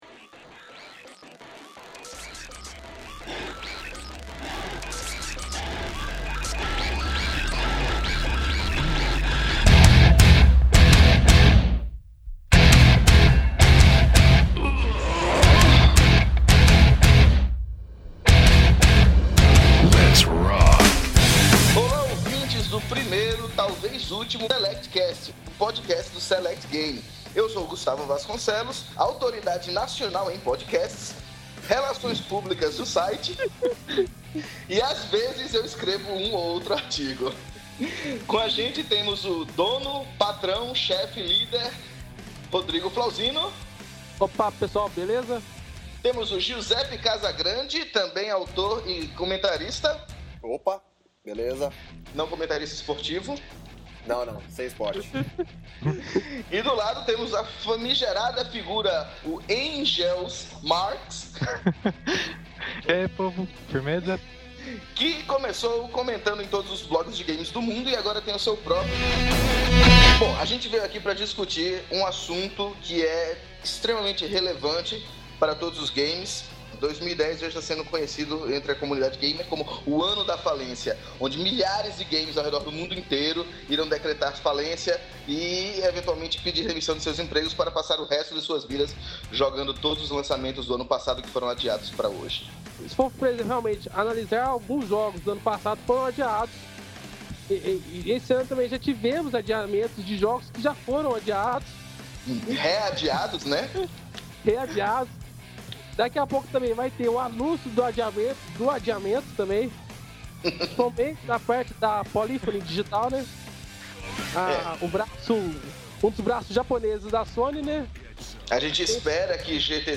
Gravado no final de janeiro, é uma experiência nossa para agradar vocês, caros futuros-ouvintes.